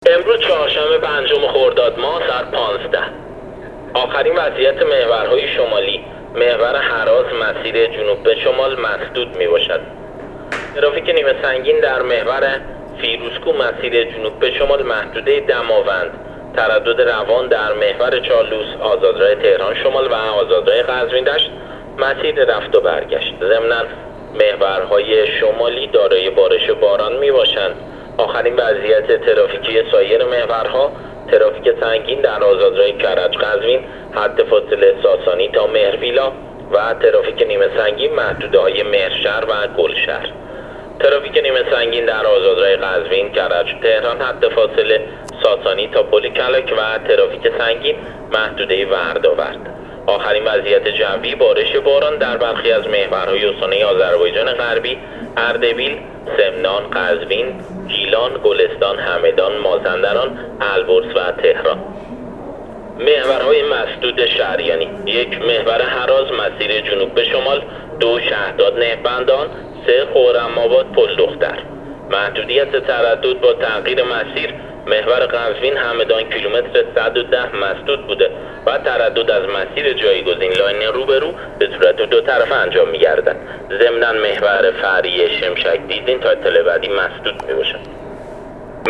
گزارش رادیو اینترنتی از آخرین وضعیت ترافیکی جاده‌ها تا ساعت ۱۵ پنجم خرداد